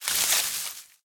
0335ec69c6 Divergent / mods / Soundscape Overhaul / gamedata / sounds / material / human / step / bush06gr.ogg 12 KiB (Stored with Git LFS) Raw History